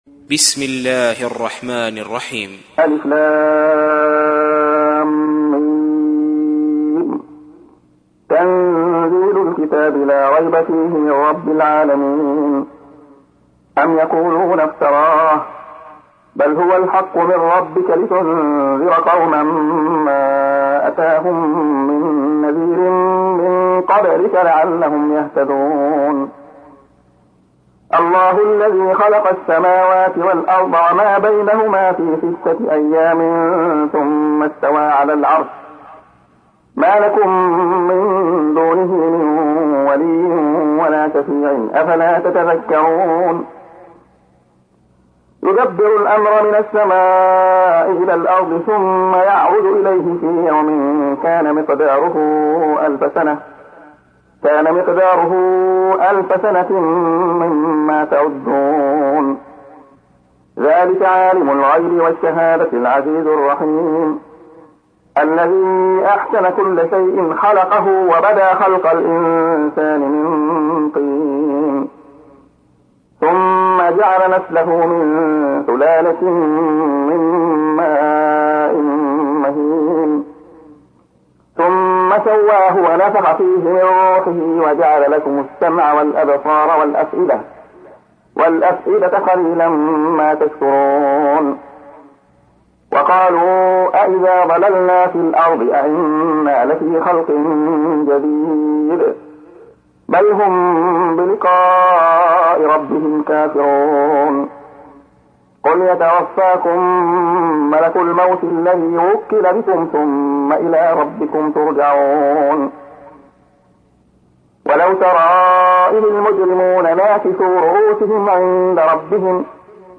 تحميل : 32. سورة السجدة / القارئ عبد الله خياط / القرآن الكريم / موقع يا حسين